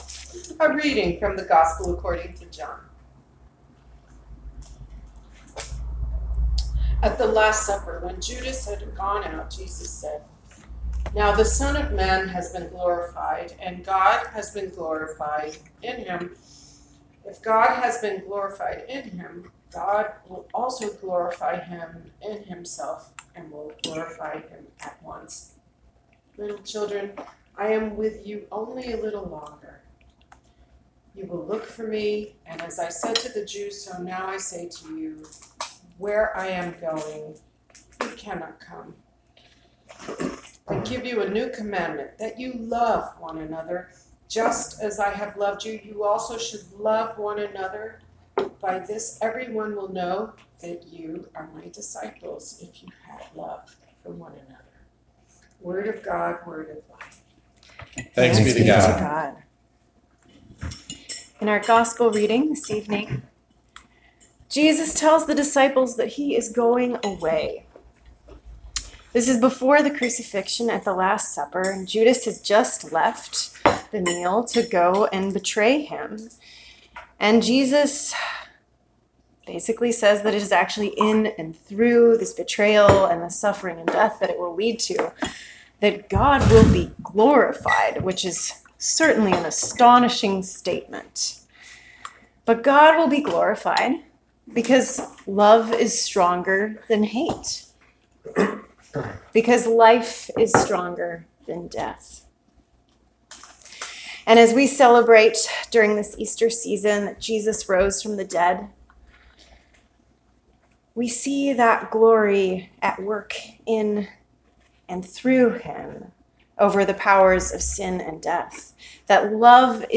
May 20, 2019 Sermon